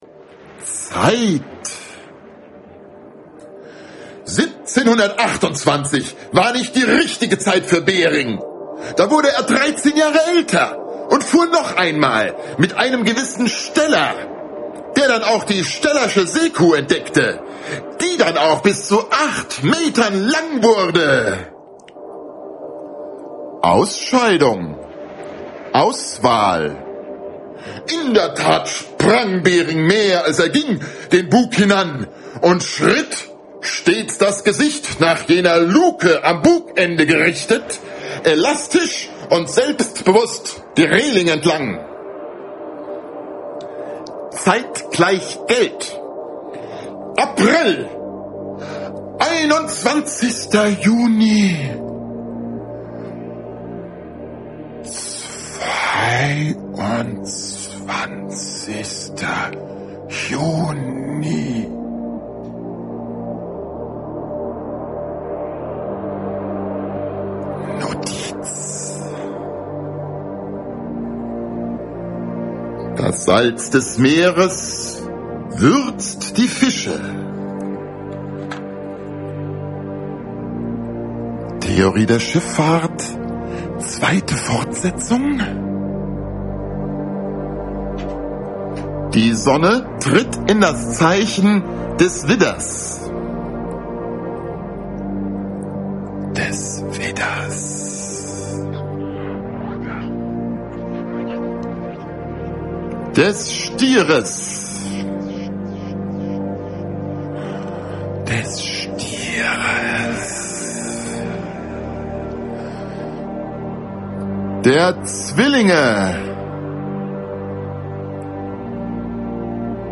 Live Auftritt in der 'Remise', Berlin Schöneberg Am Mundwerk